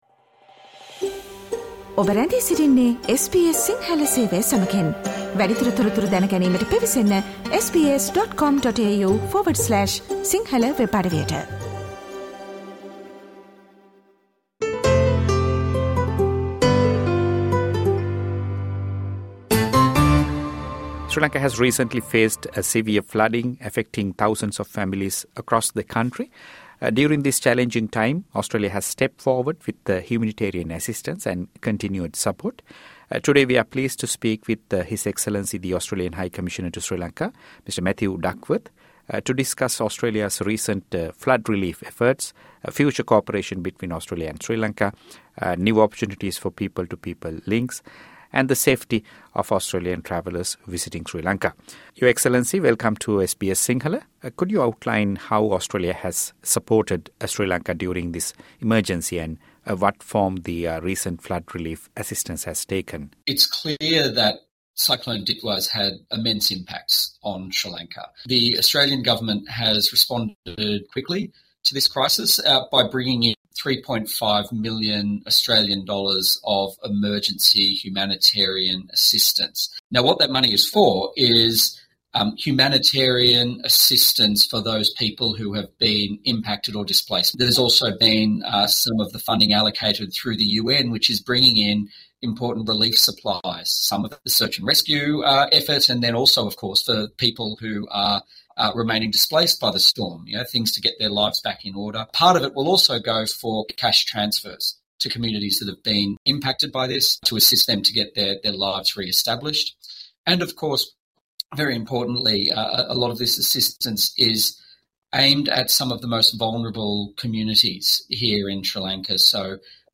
'Sri Lanka will take a long time to recover' - Australian High Commissioner Matthew Duckworth speaks to SBS Sinhala
When Cyclone Ditva devastated communities across Sri Lanka, the Australian Government and people were among the first to extend their support. In this podcast, we speak with the Australian High Commissioner to Sri Lanka, Mr Matthew Duckworth, about Australia’s assistance during this challenging period and the enduring friendship between our two nations.